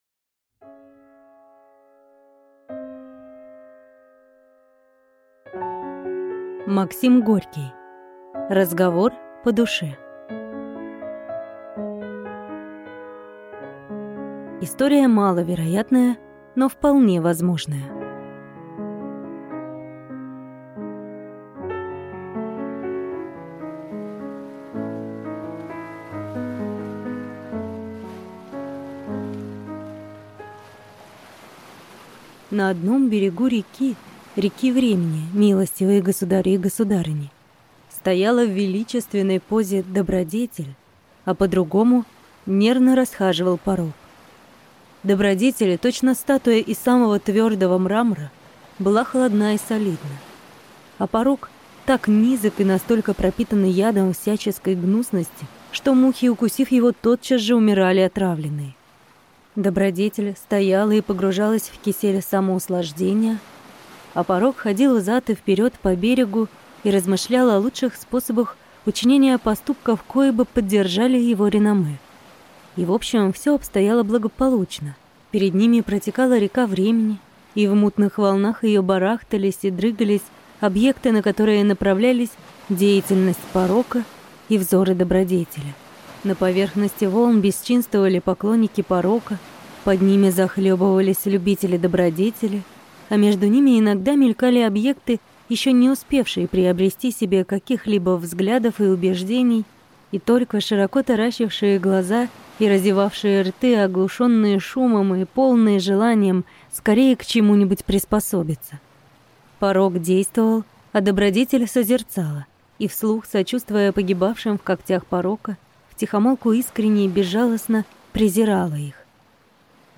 Аудиокнига Разговор по душе | Библиотека аудиокниг